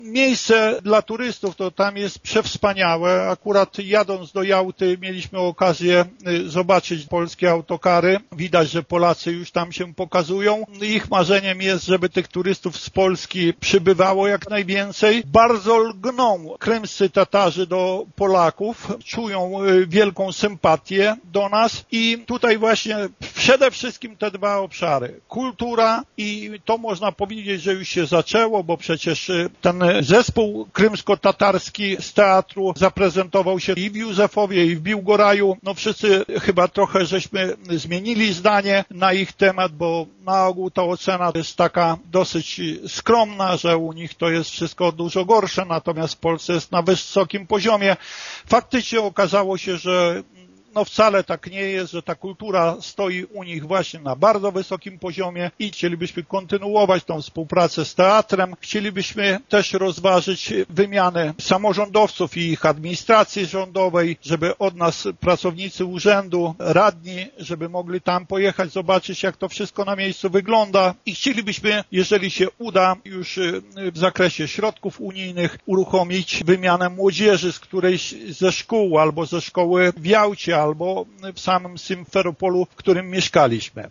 Według burmistrza Romana Dziury szanse na nawiązanie bliższych kontaktów są bardzo duże, a najbardziej realna wydaje się współpraca turystyczna i kulturalna: